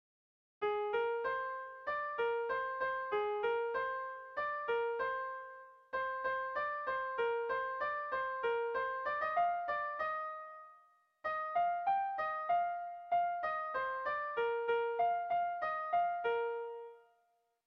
Dantzakoa
Seiko txikia (hg) / Hiru puntuko txikia (ip)
ABD